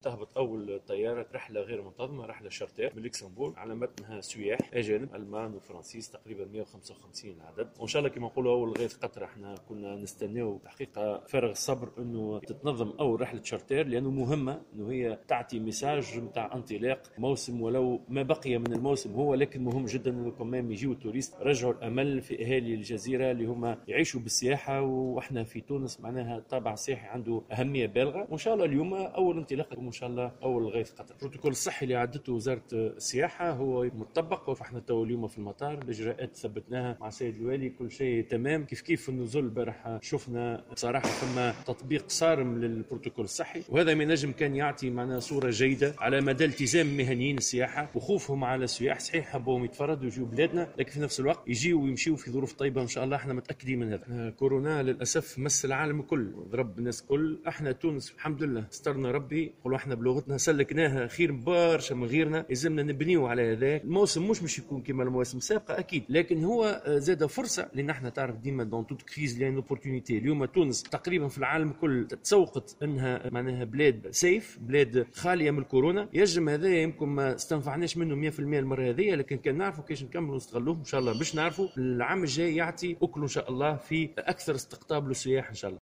واعتبر التومي في تصريح لمراسلة "الجوهرة أف أم" أن مثل هذه الرحلات بإمكانها إنقاذ ما بقي من الموسم السياحي، قائلا إن "أول الغيث قطرة".